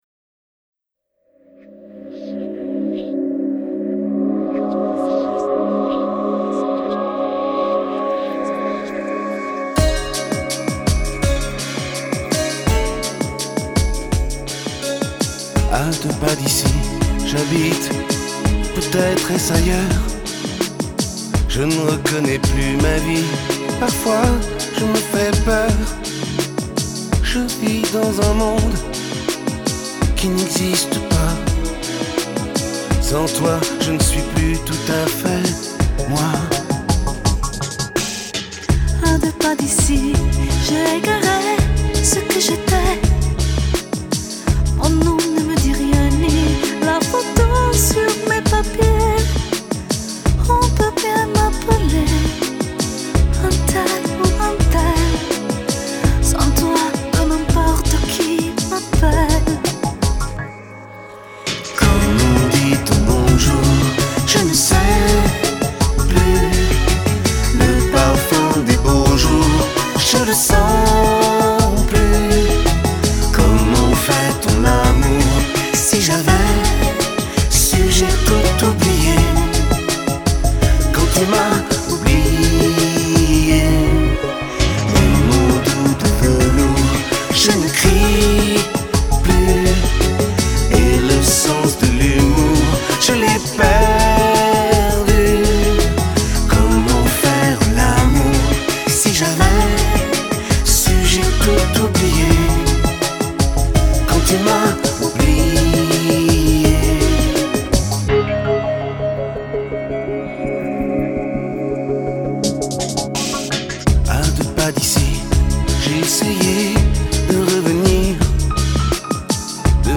Chanteuse